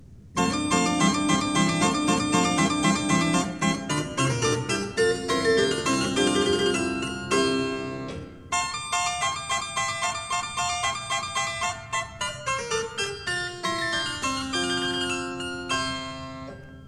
Two short harpsichord pieces